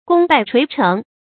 注音：ㄍㄨㄙ ㄅㄞˋ ㄔㄨㄟˊ ㄔㄥˊ
功敗垂成的讀法